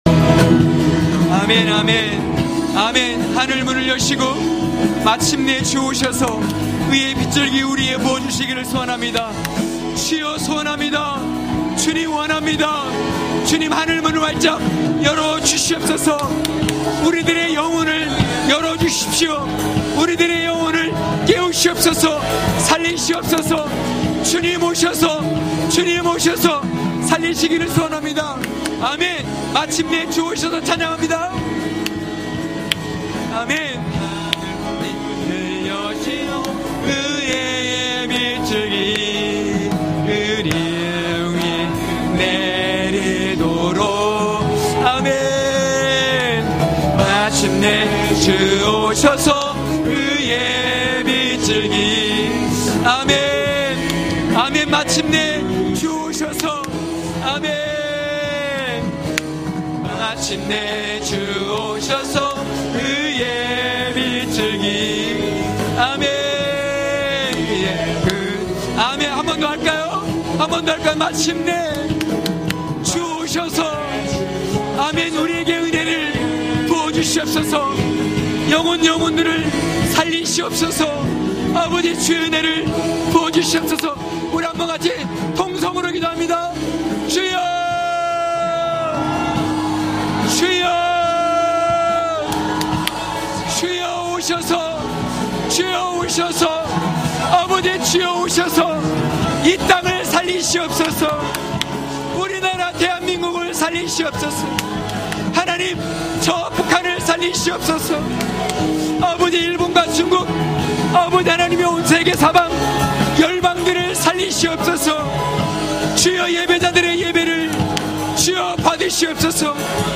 강해설교 - 8.느헤미야로 성벽이 세워집니다!(느4장18~23절).mp3